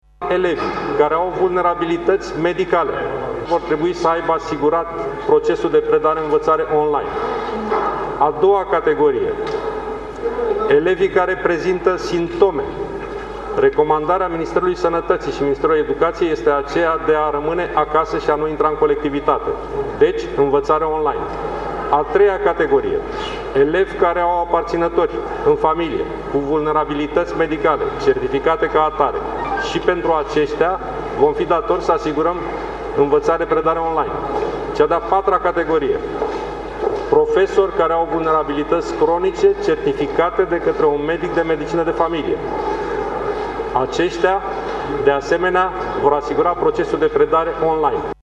În condițiile în care mâine se va decide că din data de 8 februarie se deschid școlile cu prezență fizică, vor trebui asigurate posibilități de predare online atât pentru elevii cu vulnerabilităţi medicale, cât și pentru cadrele didactice cu afecțiuni, a declarat ministrul Educației: